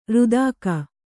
♪ rudāka